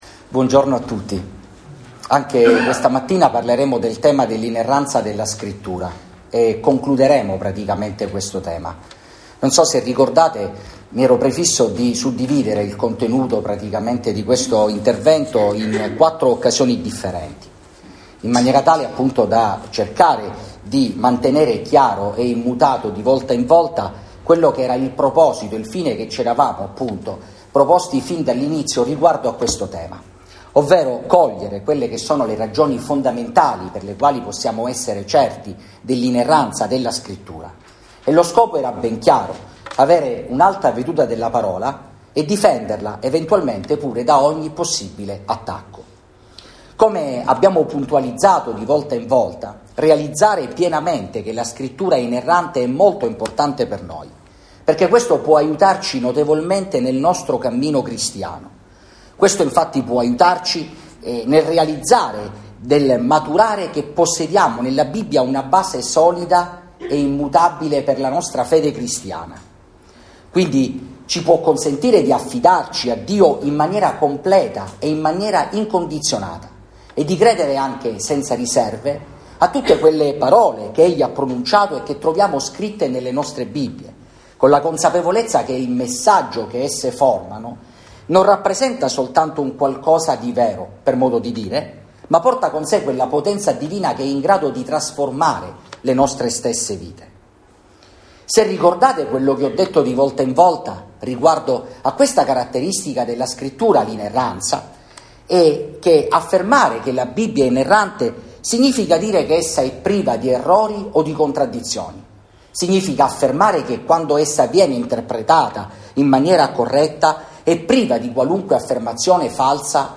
Sermoni